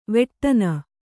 ♪ veṭṭana